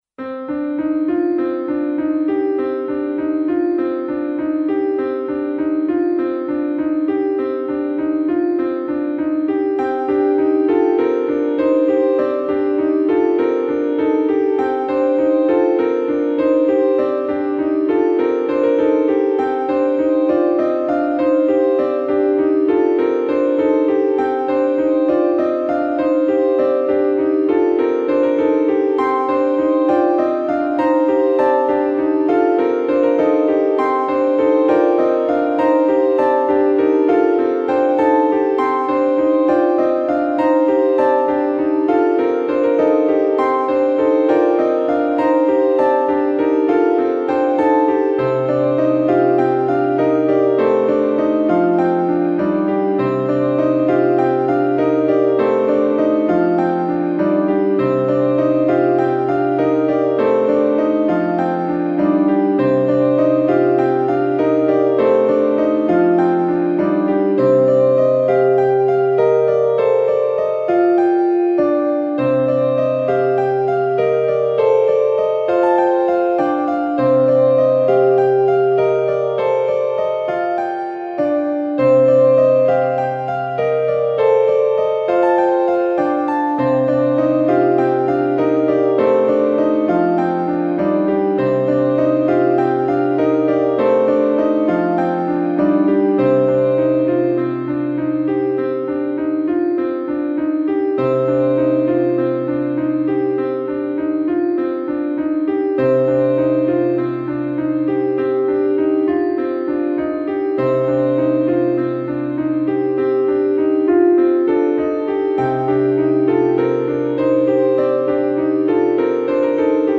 Solo Piano in Gm